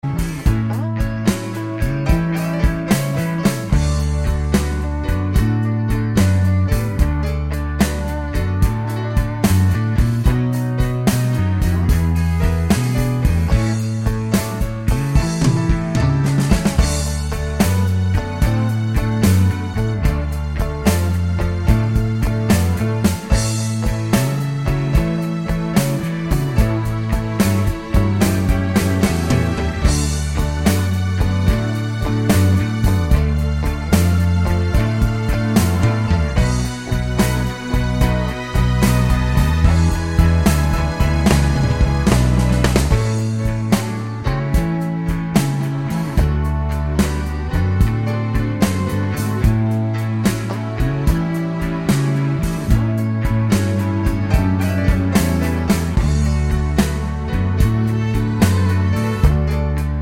no Backing Vocals Crooners 3:05 Buy £1.50